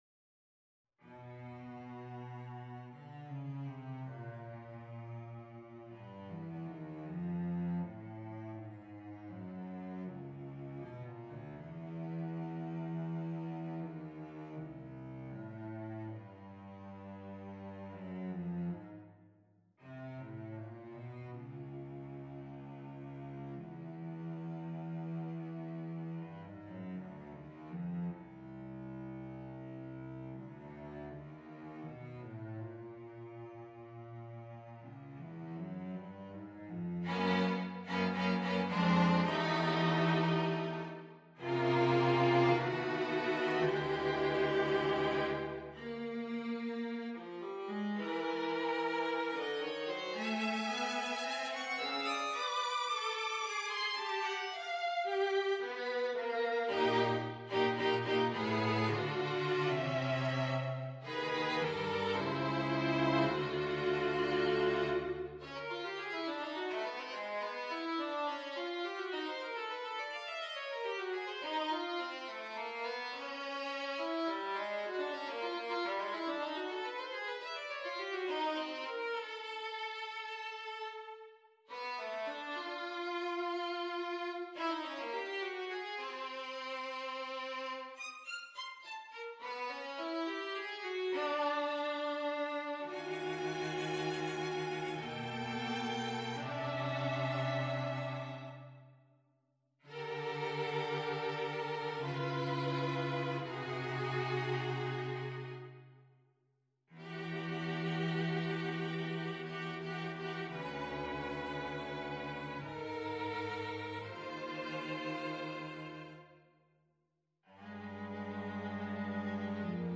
on a purpose-selected tone row
(01) Om. Largo molto (C)
(08) AUM. Vivace (E)
(12) The Self. Tempo Primo (F)
It contains only 12 short verses - each of which is briefly memorialized in the little micro-sections of this composition. Additionally, half of those sections are scored entirely for one or another solo voice, with the other half devoted to short tuttis of the whole quartet.